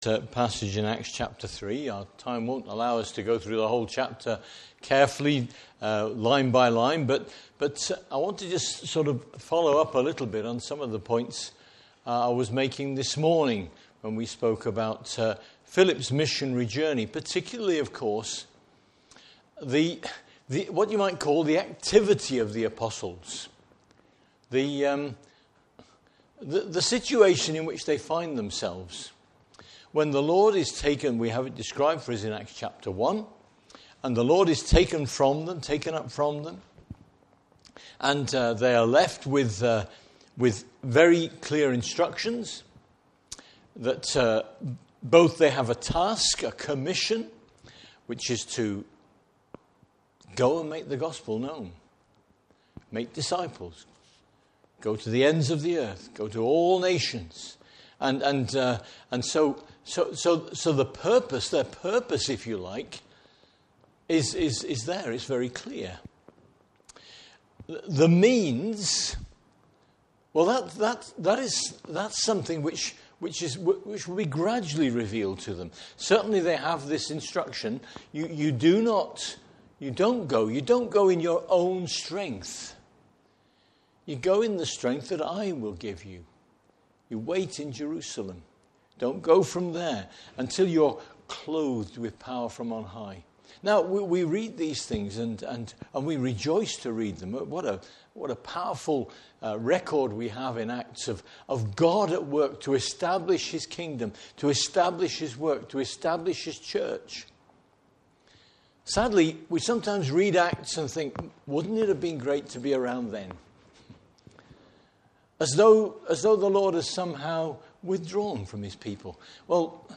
Service Type: Evening Service Bible Text: Acts 3.